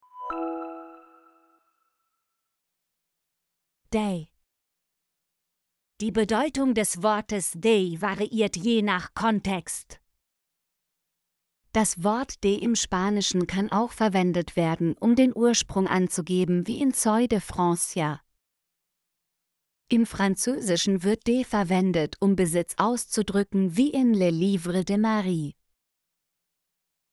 de - Example Sentences & Pronunciation, German Frequency List